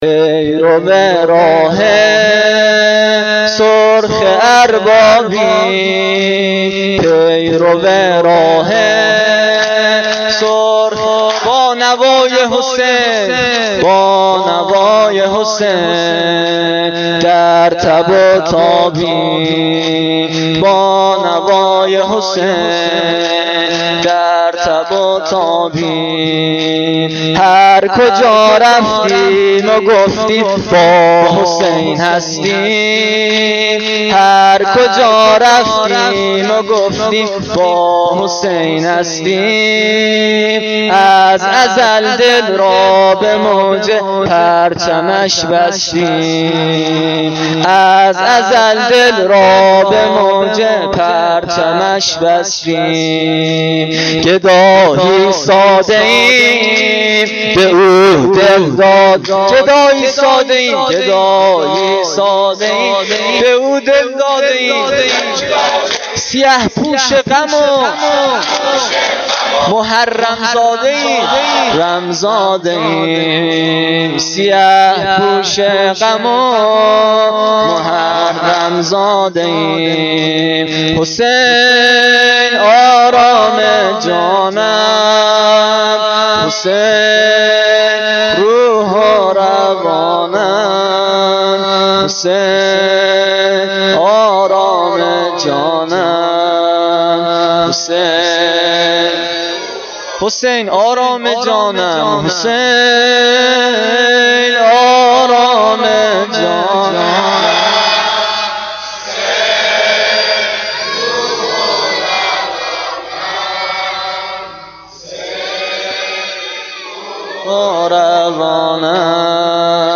دم پایانی شب هشتم محرم1393